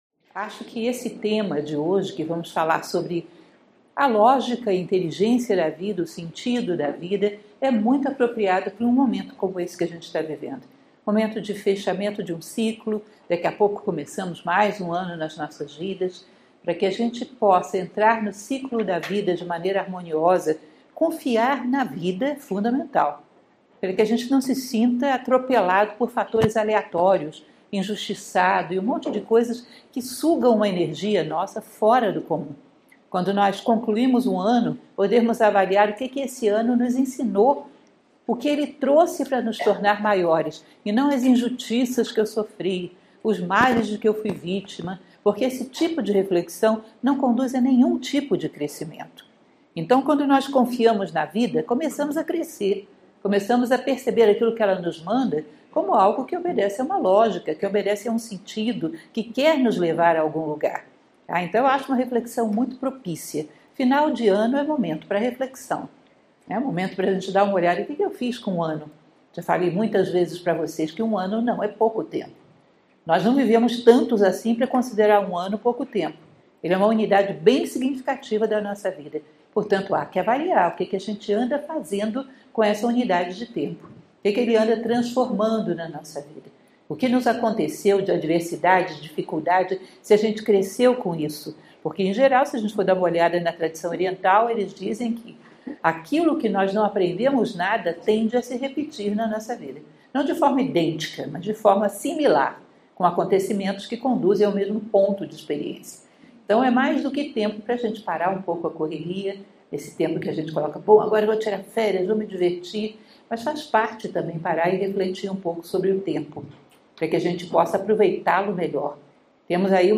Palestras Filosóficas Nova Acrópole